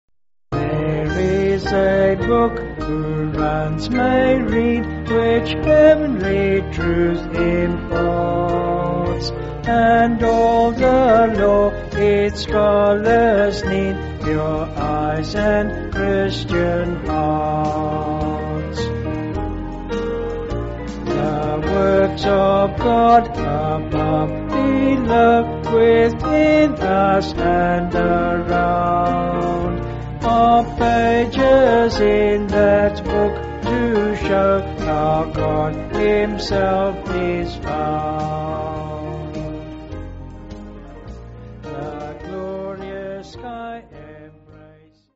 (BH)   7/Eb
Vocals and Band